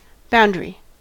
boundary: Wikimedia Commons US English Pronunciations
En-us-boundary.WAV